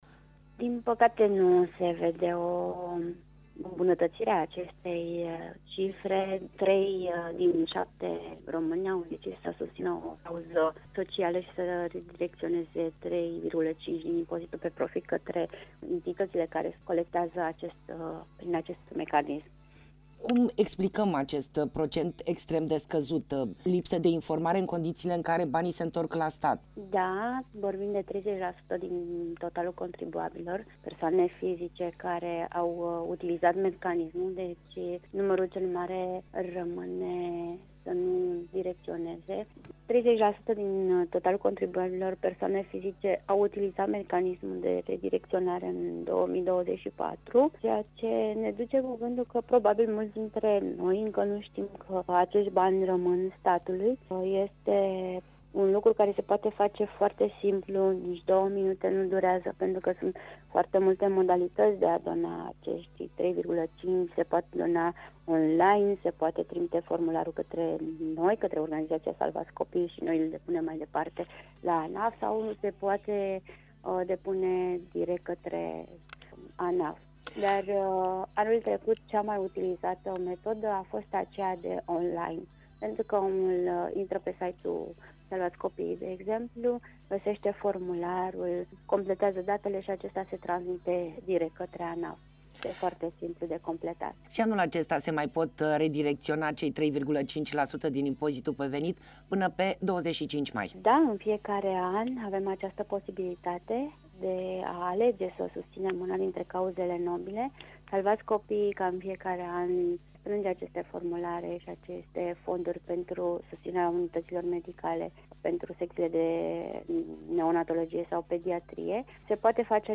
Interviu-salvati-copiii-topul-darniciei.mp3